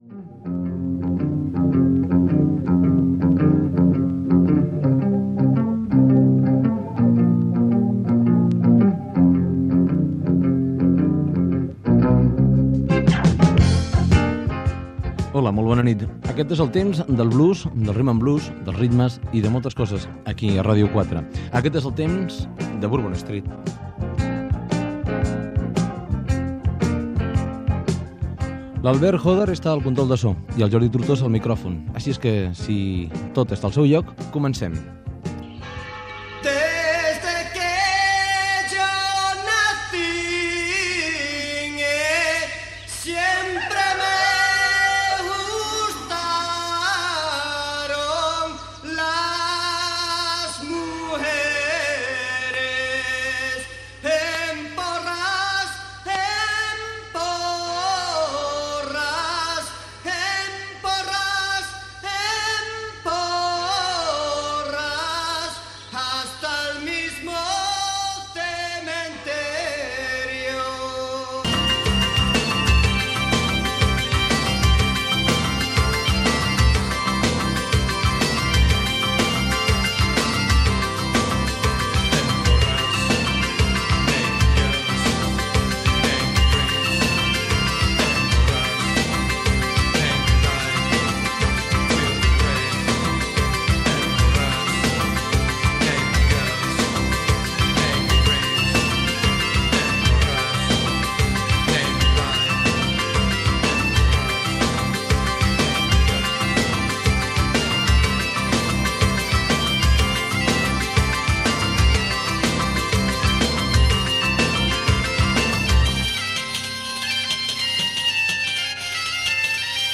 Sintonia, presentació, tema musical, comentari del tema escoltat i nou tema
Musical